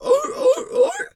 seal_walrus_2_bark_03.wav